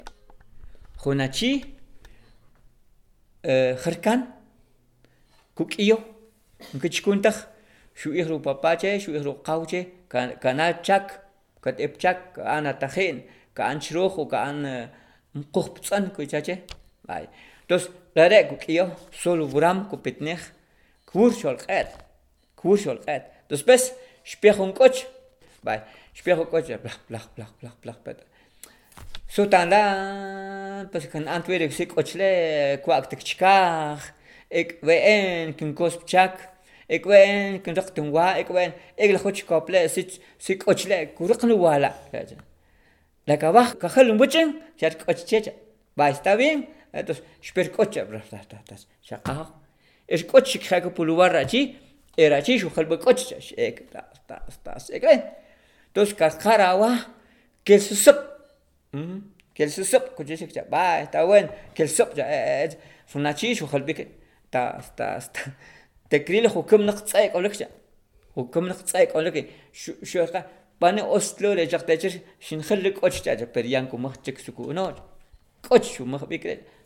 (Note that the audio files linked here have been edited to remove errors and repetitions, but the complete, original files, exactly as recorded, can be accessed through through the Archive of the Indigenous Languages of Latin America.)